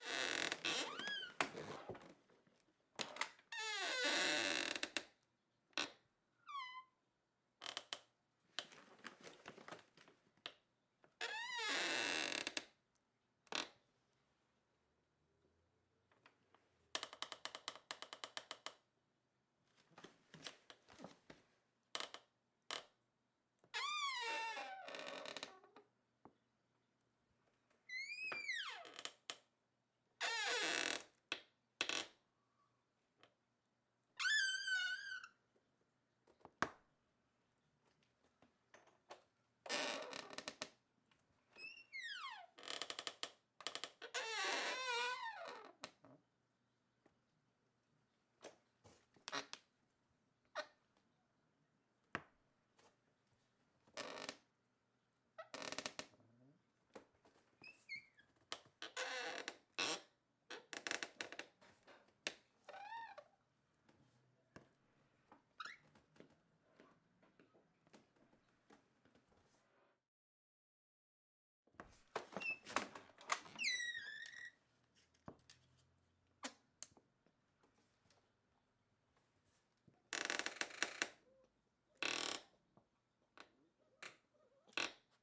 随机 " 木制浴室门吱吱作响的小
描述：木浴室门吱吱作响small.wav
Tag: 浴室 吱吱作响